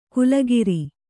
♪ kulagiri